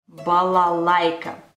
Audio file of the word "Balalaika"
Balalaika-word.mp3